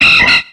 Cri d'Hélédelle dans Pokémon X et Y.